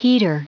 Prononciation du mot heater en anglais (fichier audio)
Prononciation du mot : heater